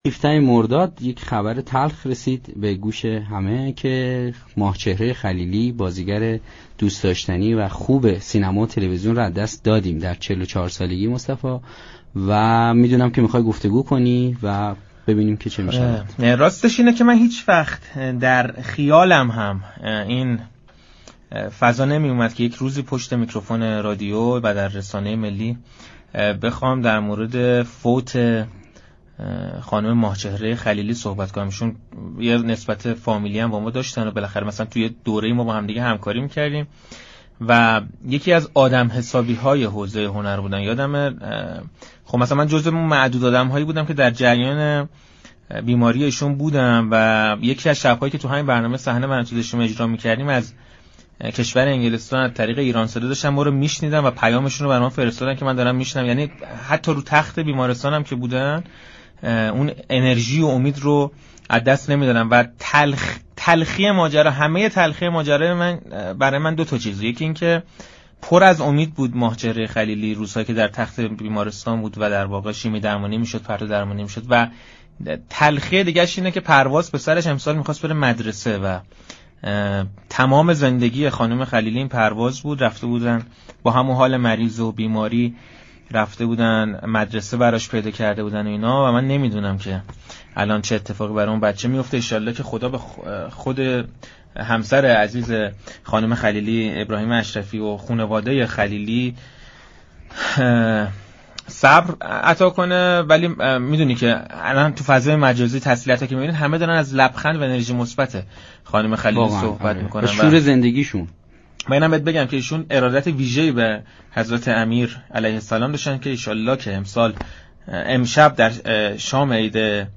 نعیمه نظام دوست، بازیگر مطرح سینمای ایران در خصوص فوت ماه‌چهره خلیلی با صحنه رادیو تهران گفتگو كرد.